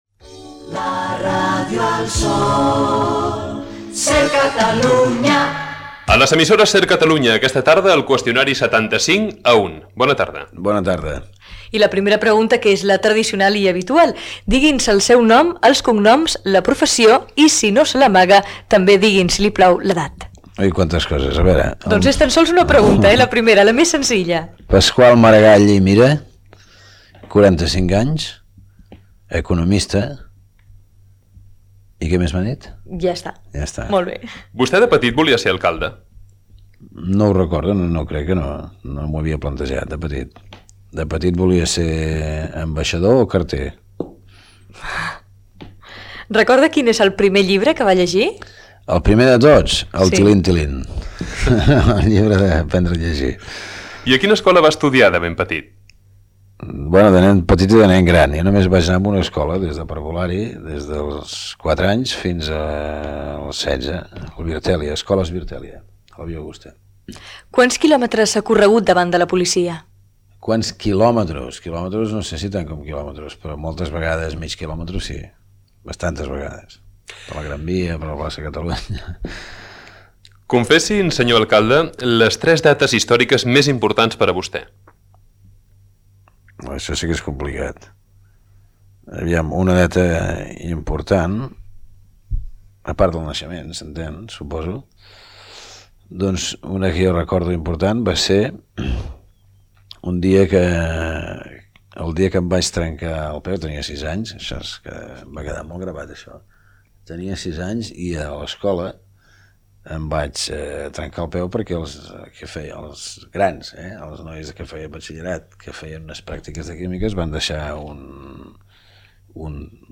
Indicatiu del programa i de l'emissora, El "Qüestionari 75 a 1": entrevista a l'alcalde de Barcelona Paqual Maragall
Entreteniment